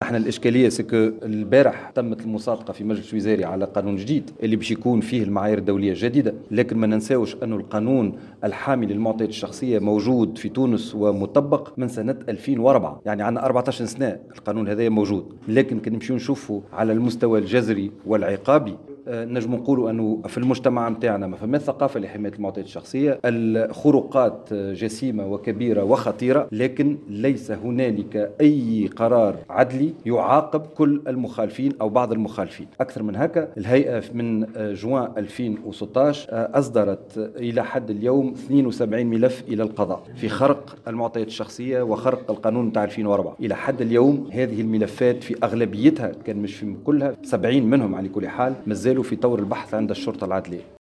وأضاف في تصريح لمراسل الجوهرة اف ام خلال حضوره افتتاح ورشة عمل نظمها الهيئة حول النظام القانوني للدعاوى الجَزائية في الجرائم المتعلقة بحماية المعطيات الشخصية، أن المجلس الوزاري الذي انعقد أمس الخمس صادق على قانون جديد يتضمن المعاير الدولية الجديدة لحماية المعطيات الشخصية.